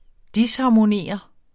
Udtale [ ˈdishɑmoˌneˀʌ ]